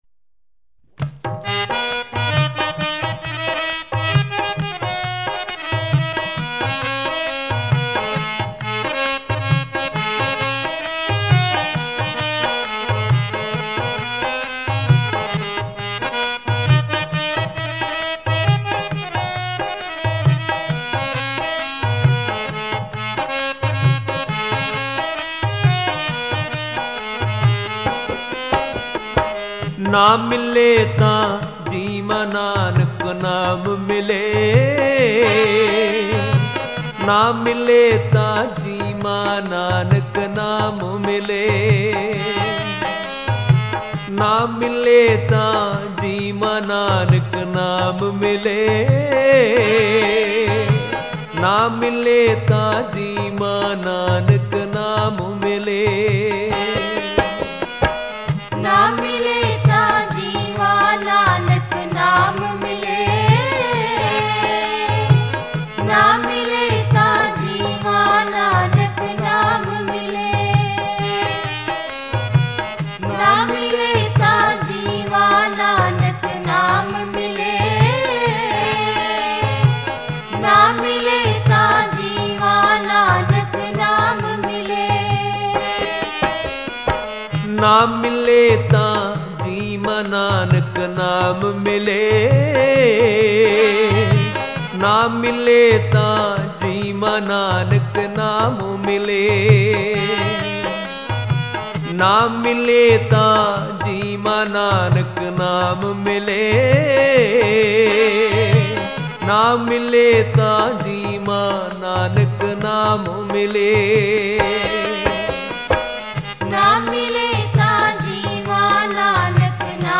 Genre: Gurmat Vichar